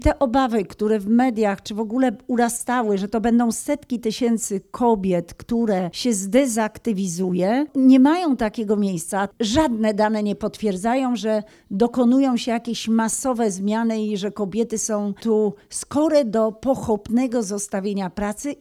W ramach omówienia dotychczasowego funkcjonowania programu Rodzina 500 Plus do Stargardu przyjechała minister rodziny, pracy i polityki społecznej Elżbieta Rafalska.
Minister Elżbieta Rafalska odniosła się też do sygnałów o tym, że Rodzina 500 Plus negatywnie wpływa na rynek pracy.